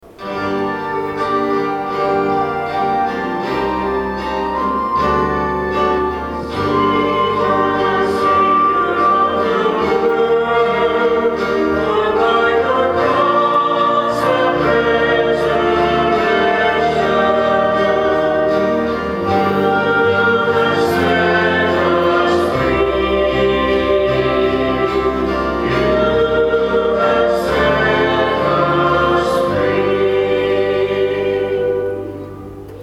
Music from the 10:30 Mass on the 1st Sunday in Lent, March 24, 2013: